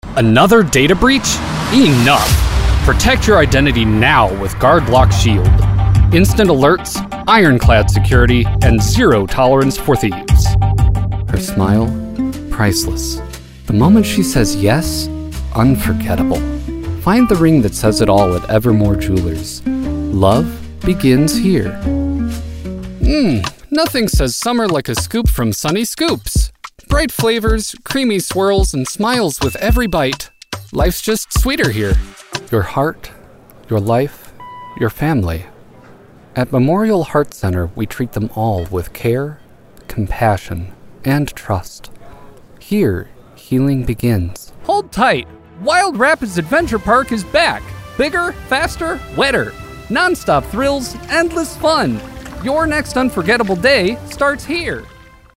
I offer you sincerity, humor, and earnestness across a spectrum of sound from genuine to goofy, opera to metal, corporate to coffee shop, and a few things in between.
Commercial Demo